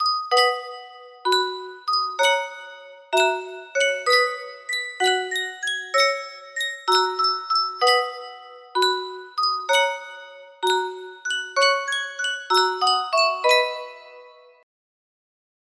Sankyo Music Box - The Bowery BAH music box melody
Full range 60